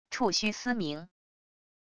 触须嘶鸣wav音频